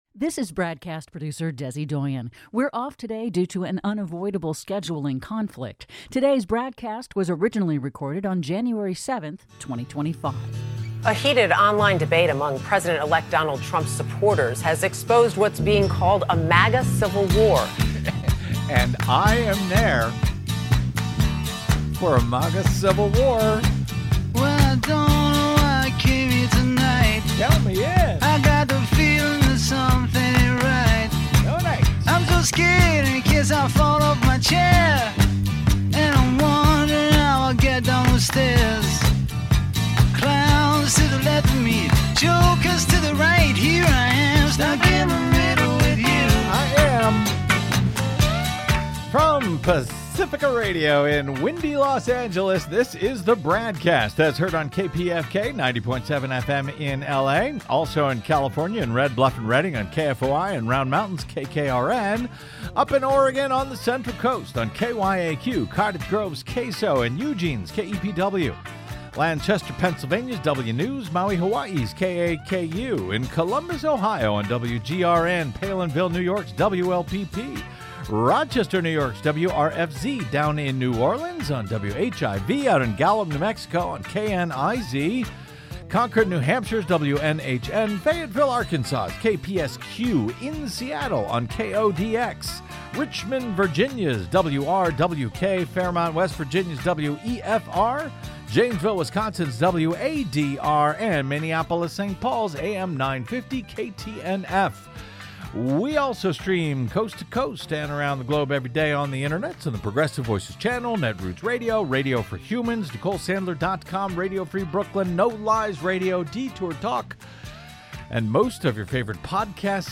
Originating on Pacifica Radio's KPFK 90.7FM in Los Angeles and syndicated coast-to-coast and around the globe!